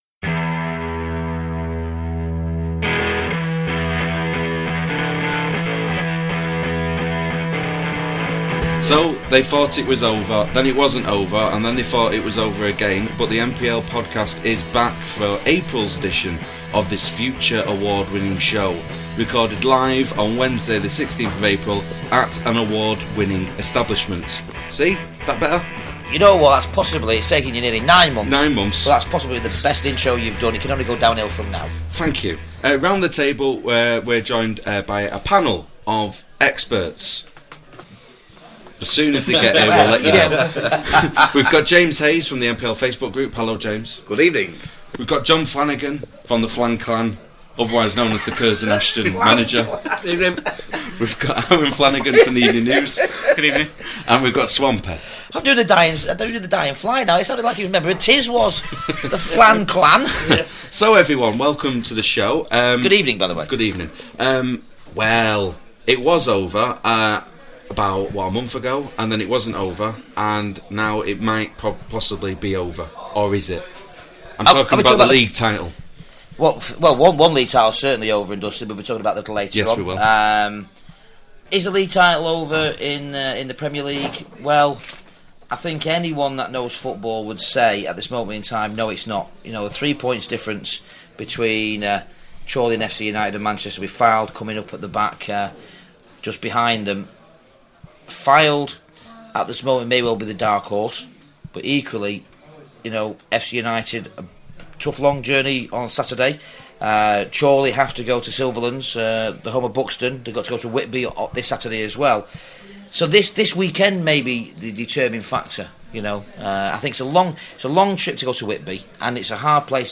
With Thanks: This programme was recorded at the Magnet in Stockport on Wednesday 16th April 2014.